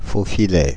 Prononciation
Synonymes aloyau contre-filet contrefilet Prononciation Paris: IPA: [fo.fi.lɛ] France (Paris): IPA: /fo.fi.lɛ/ Le mot recherché trouvé avec ces langues de source: français Traduction 1.